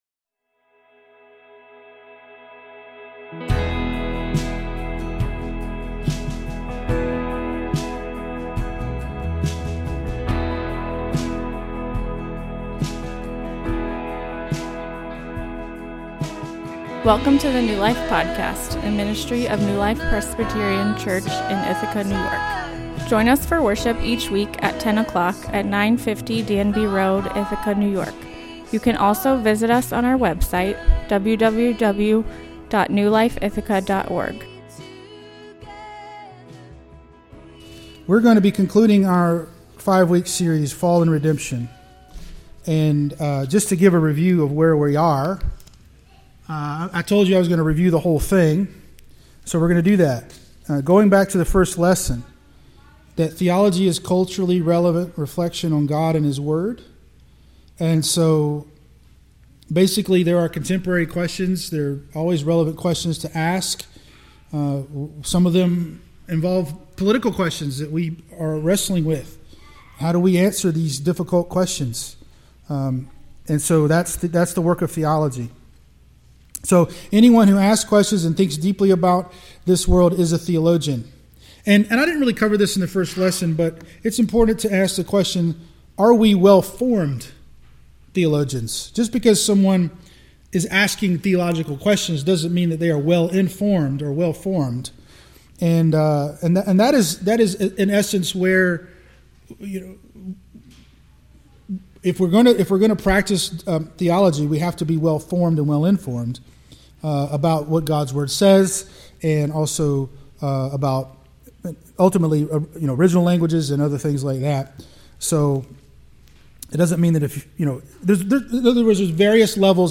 This is the final class in a five week Christian education class called Theo 100, an introduction to Christian Theology. This week we learn how evil, pain, and sorrow came into the world and what God is going to do about it.